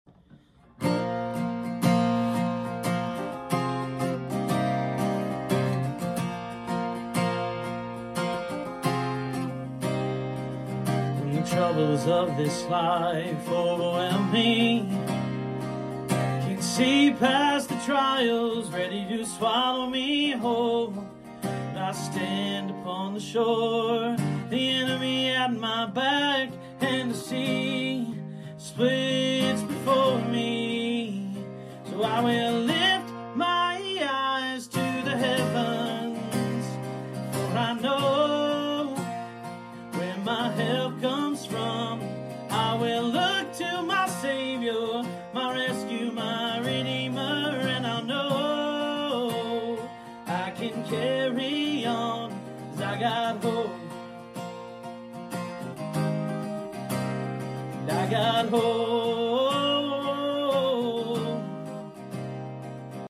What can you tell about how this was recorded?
This is a small snippet of the song I wrote around this.